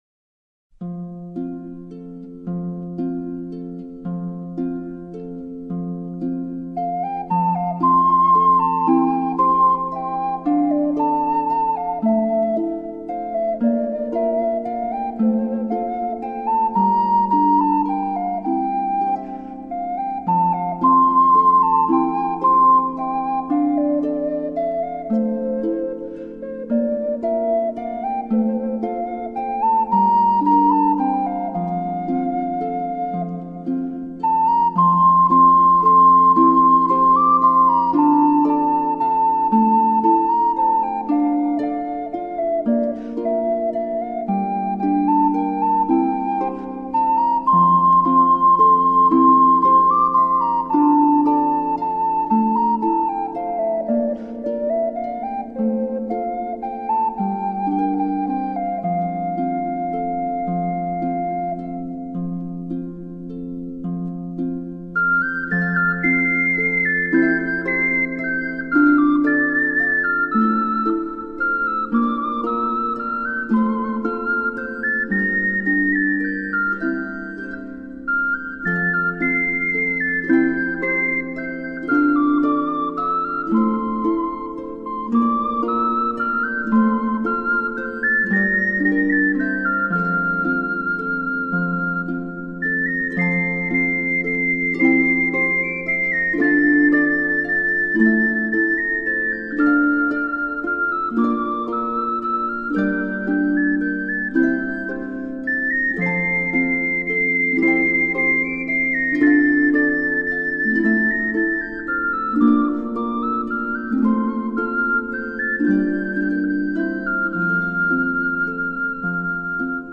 Ocarina cover ♪ Anime Music